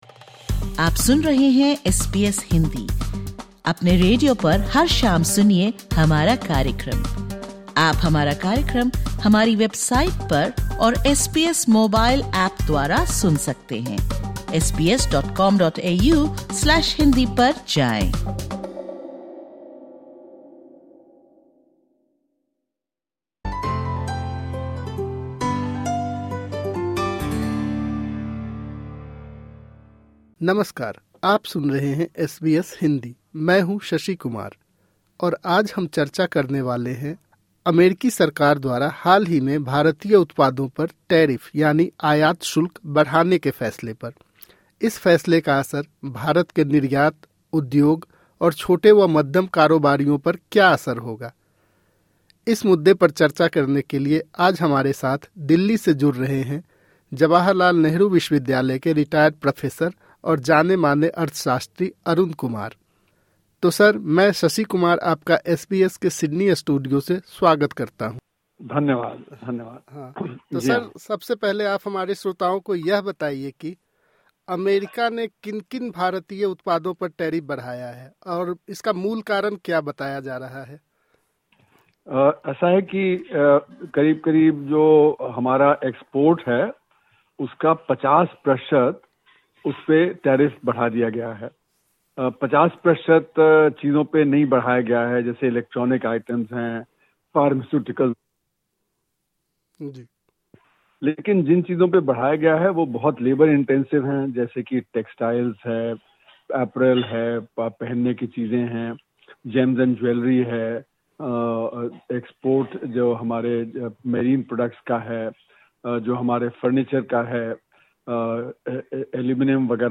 (Disclaimer: The information given in this interview is of a general nature.